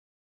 surface_felt6.mp3